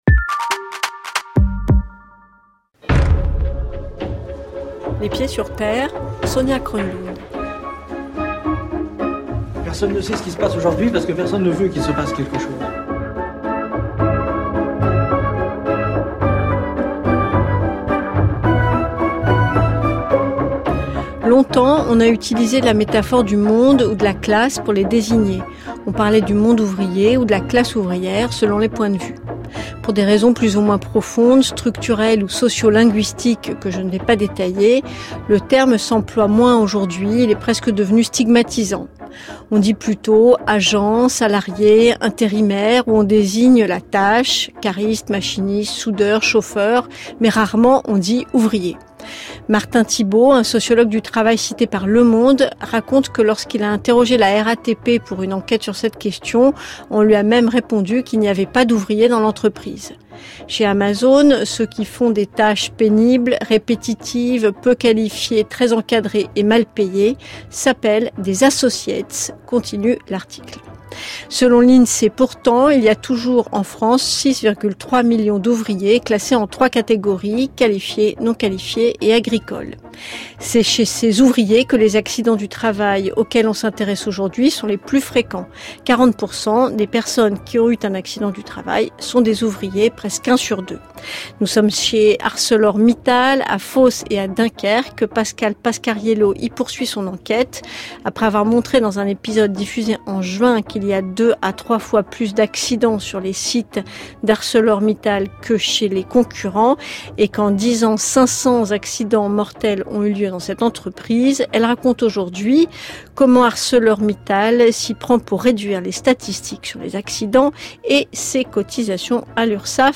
FRANCE CULTURE (2018) La sortie de prison- Documentaire Les pieds sur Terre